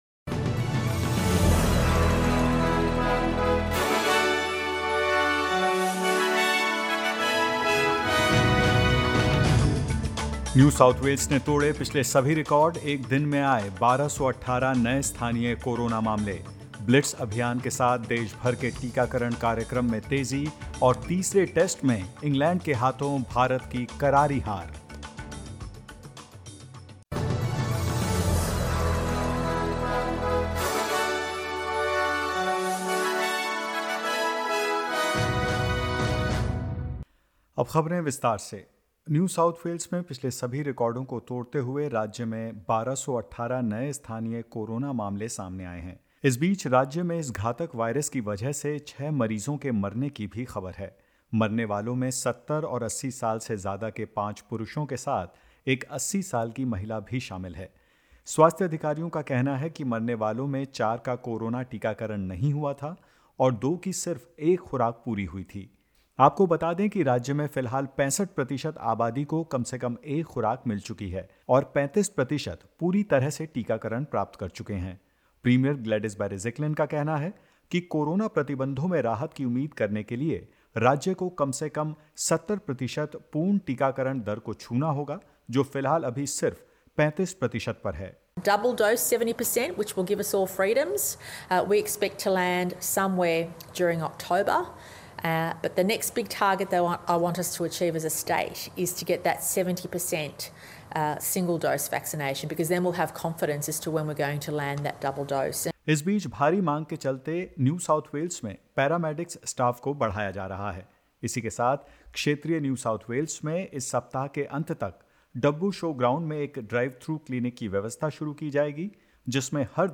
In this latest SBS Hindi News bulletin of Australia and India: Queensland records one community case in home quarantine; Victoria records 92 new locally acquired cases, including more than 30 not yet linked to existing outbreaks and more.